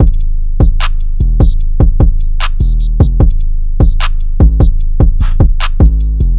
EN - Loop V (130 BPM).wav